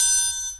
SIZZLE-TRIANGLE2.wav